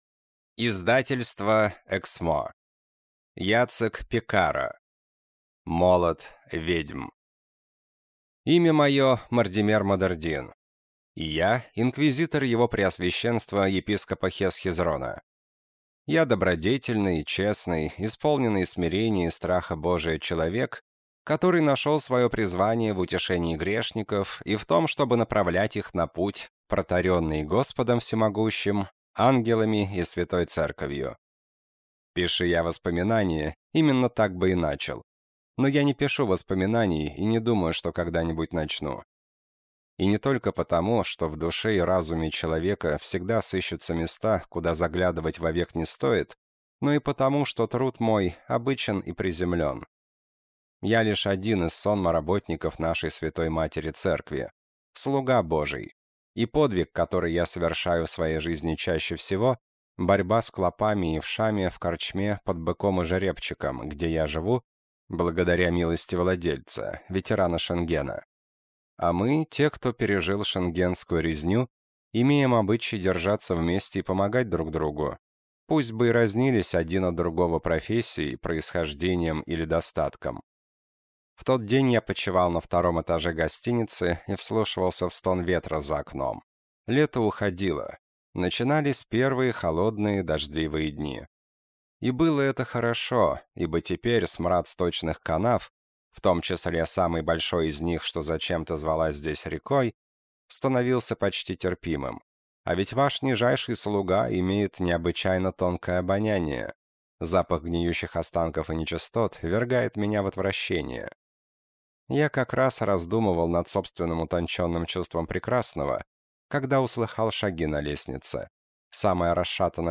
Аудиокнига Молот ведьм | Библиотека аудиокниг